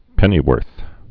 (pĕnē-wûrth)